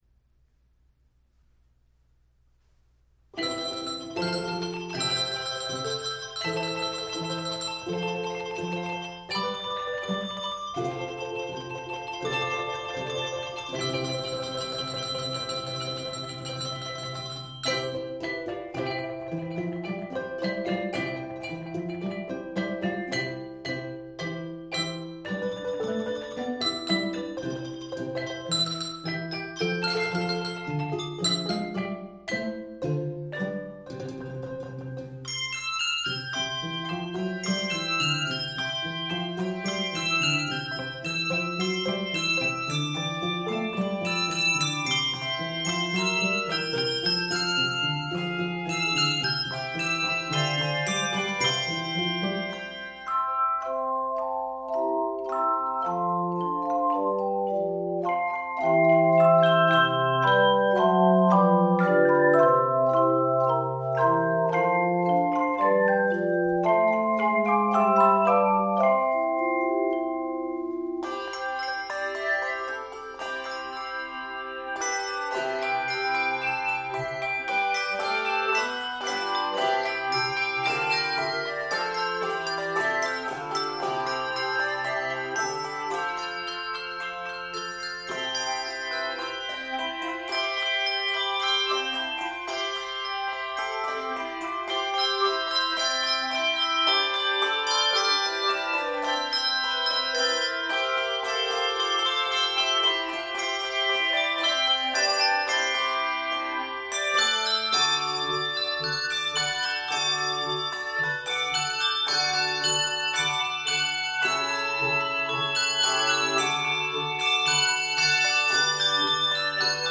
Keys of C Major and D Major.